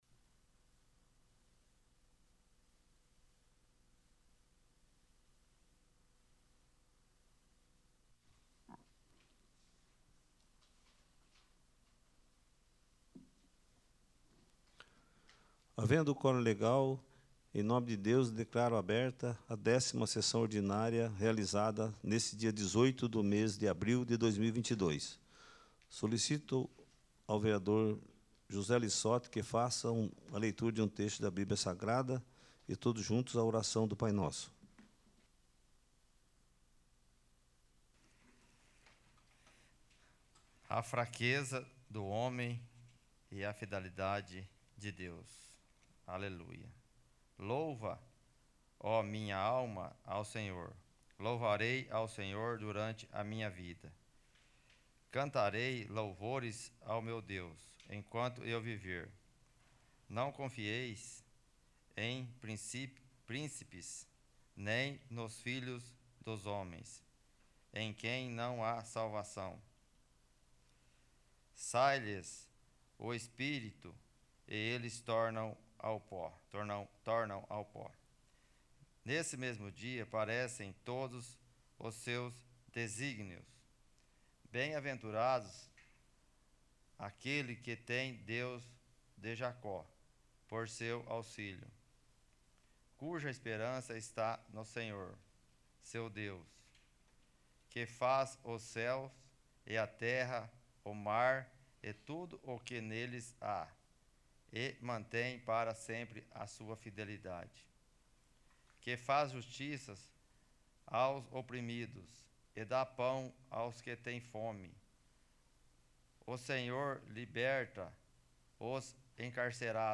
10º. Sessão Ordinária